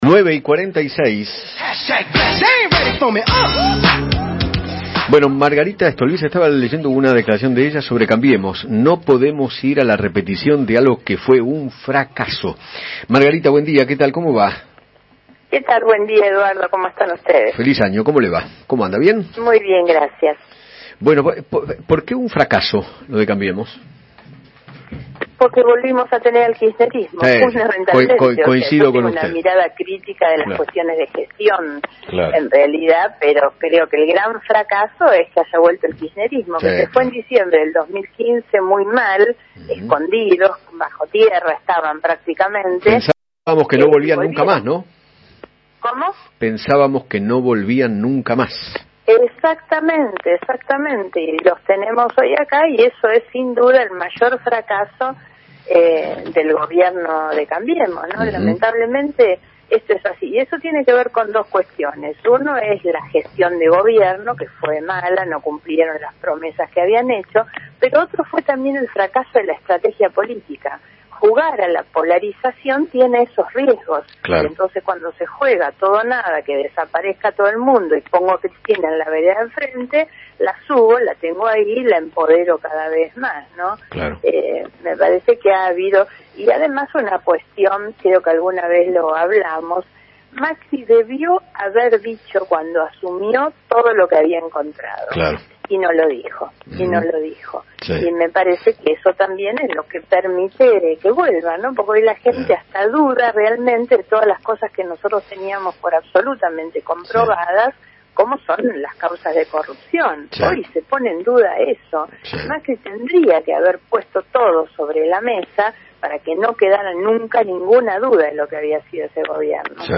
Margarita Stolbizer, presidenta del partido GEN, dialogó con Eduardo Feinmann sobre el Gobierno de Mauricio Macri y opinó en qué falló durante su gestión.